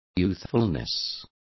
Complete with pronunciation of the translation of youthfulness.